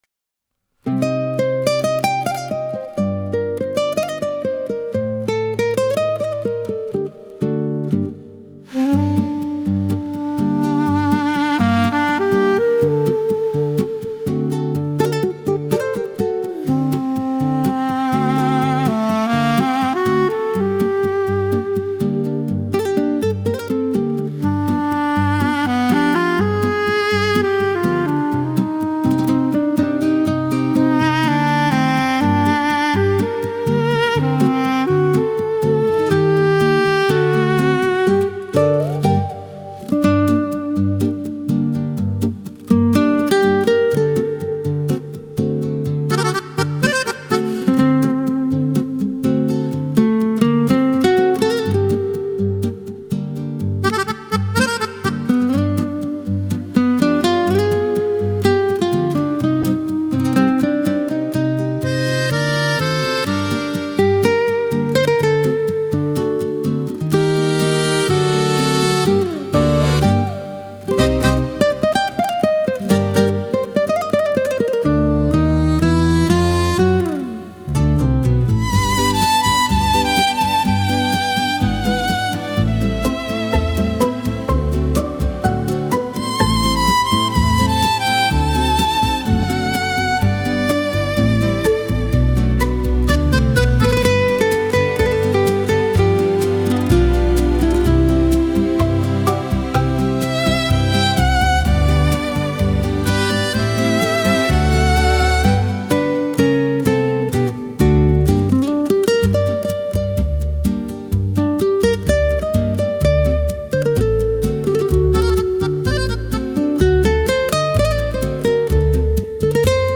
nuova versione Latin Rumba Tango / Rumba
Una reinterpretazione strumentale latin rumba
emozionante e coinvolgente.)